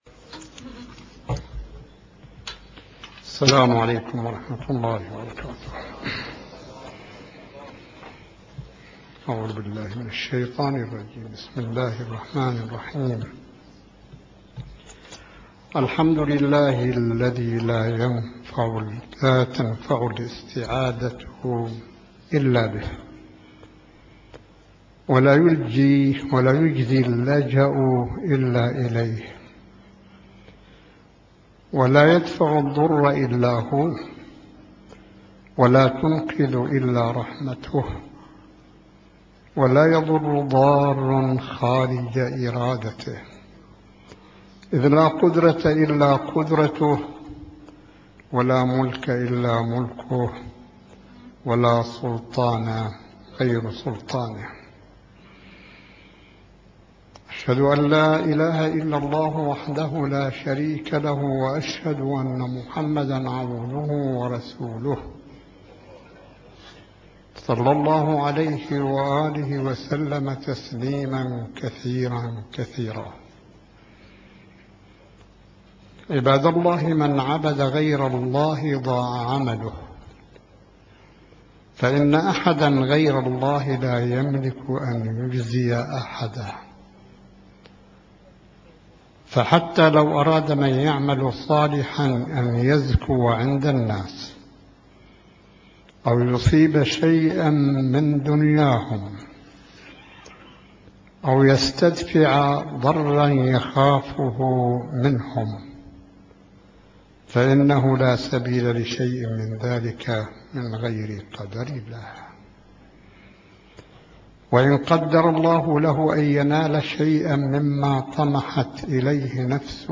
صوت: خطبة الجمعة (550) 1 جمادى الثاني 1434 هـ ـ 12 أبريل 2013م